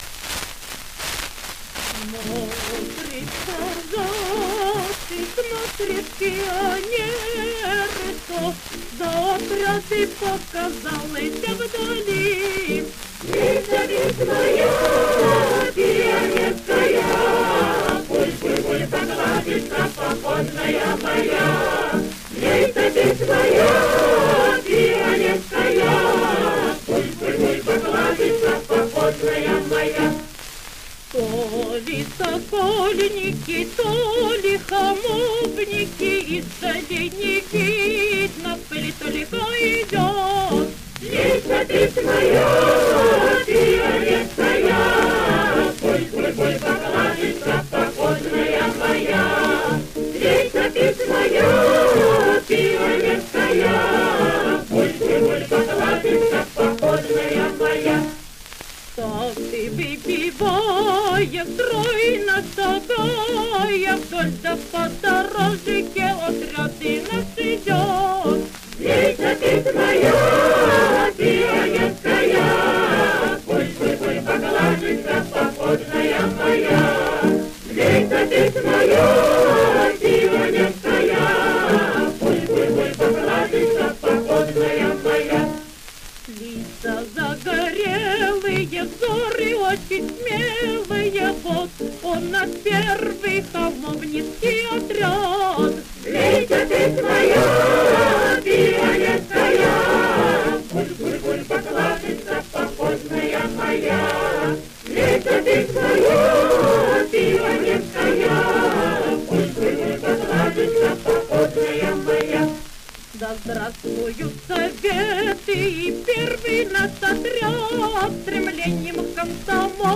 Неважную по качеству пластинку изготовили на подмосковной фабрике с гордым названием «Памяти 1905 года» — изготовили на старом изношенном оборудовании, оставшемся фабрике «в наследство» от дореволюционного общества «Граммофон»:
Пластинка